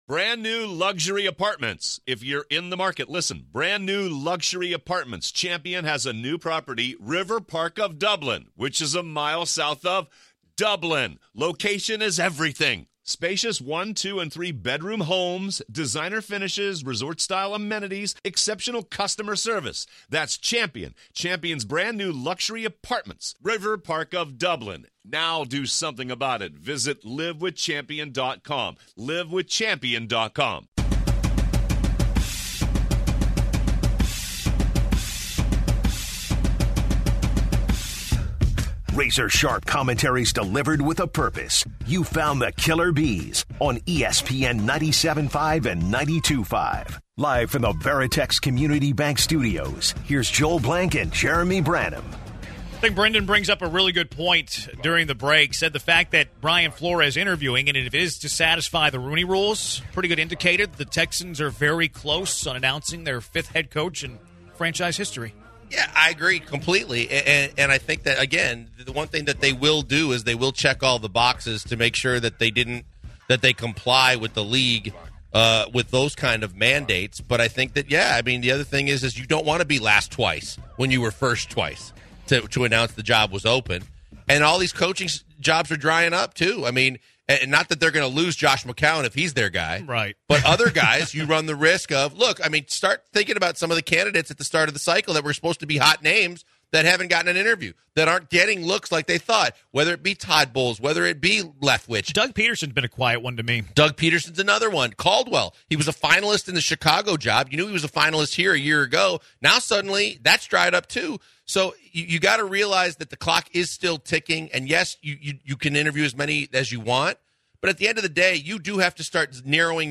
In the second hour the guys discuss the Tom Brady retirement saga that took place over the weekend and the ripple effect that could ensue if Brady is done then they switch gears to a new name that should be on the coaching carousel. Bottom of the hour former New England Patriot Ted Johnson calls the show to discuss the Texans head coaching search and they wrap up the hour with some thought they had during their conversation with Ted Johnson.